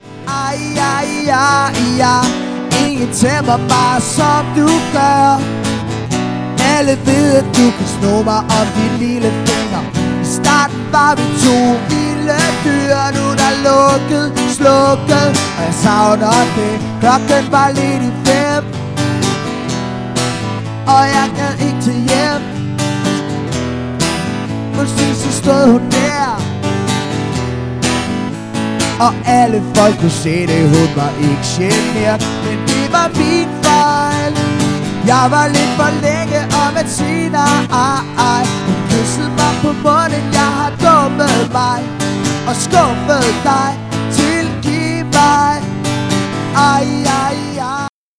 • Coverband
• Solomusiker